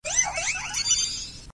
Archivo:Grito de Carbink.ogg